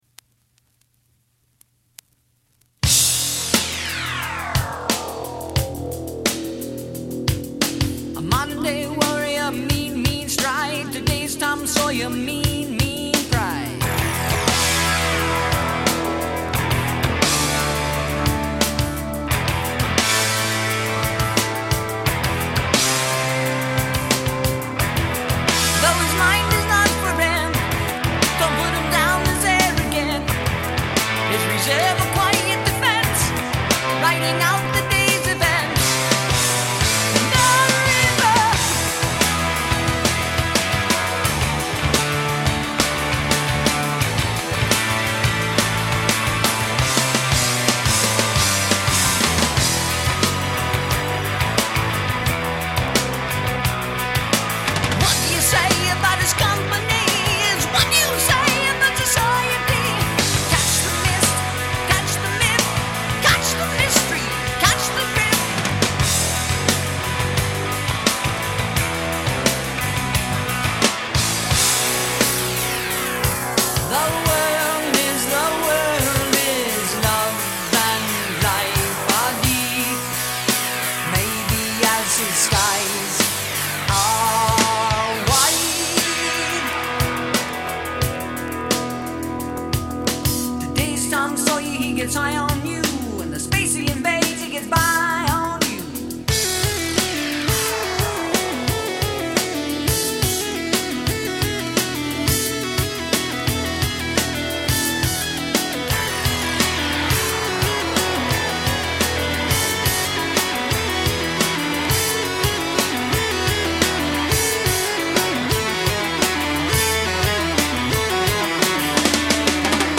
recorded from vinyl